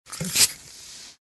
Звуки бенгальских огней
Шорох возгорания бенгальского огня